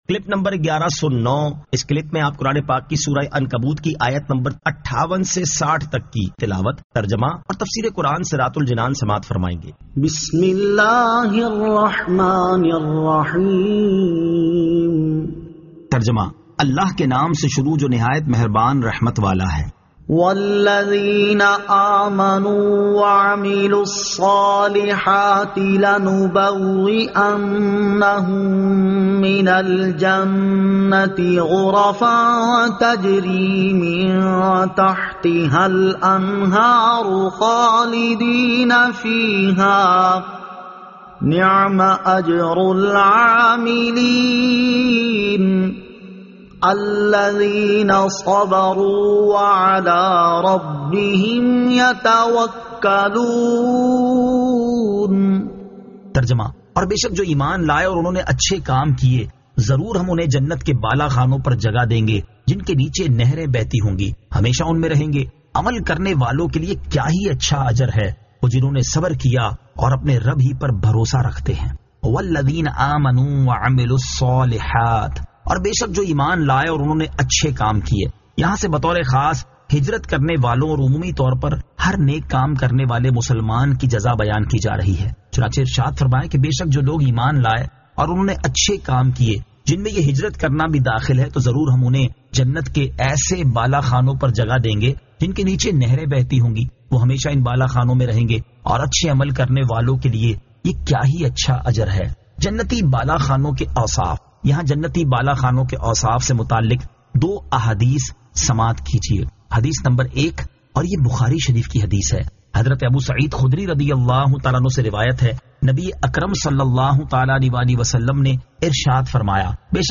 Surah Al-Ankabut 58 To 60 Tilawat , Tarjama , Tafseer